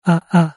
It's pronounced like ah-ah.
ah-ah.mp3